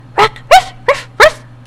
ROCKRUFF.mp3